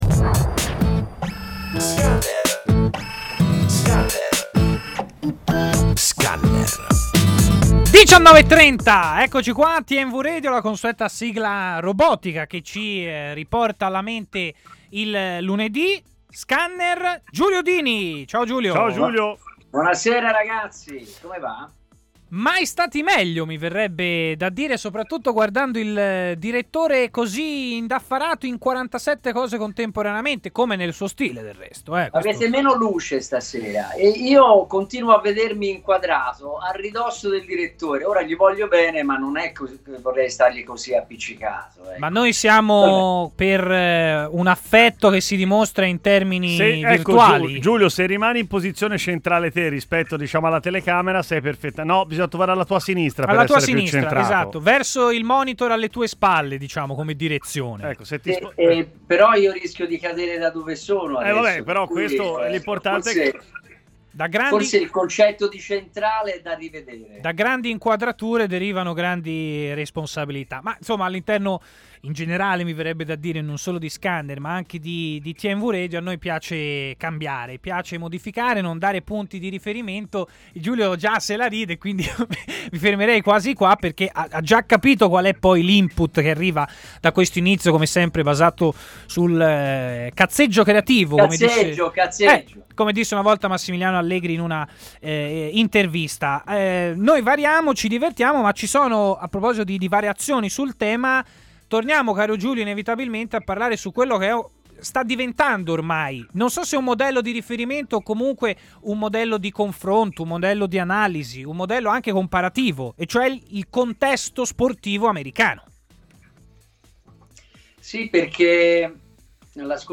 è intervenuto in diretta durante Scanner, trasmissione di TMW Radio